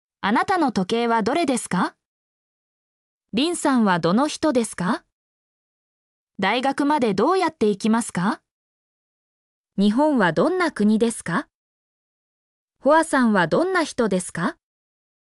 mp3-output-ttsfreedotcom-69_ZVWQUNuF.mp3